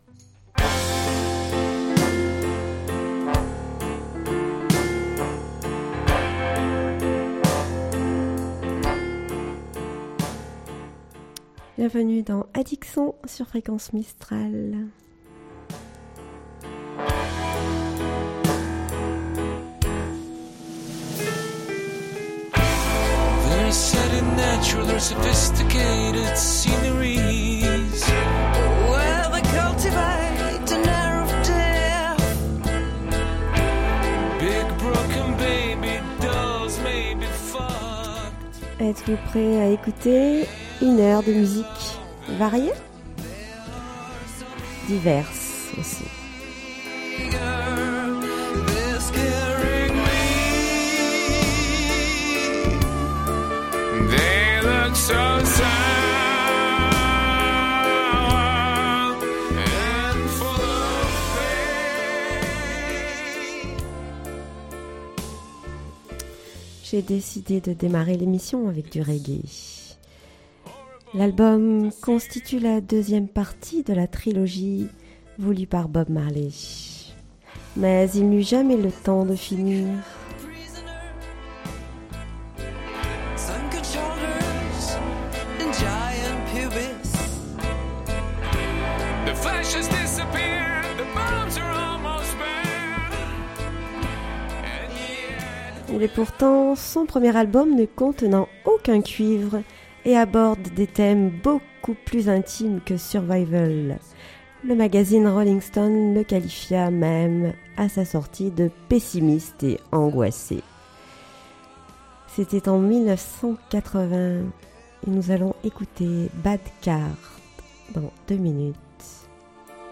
Addic son, une émission musicale proposée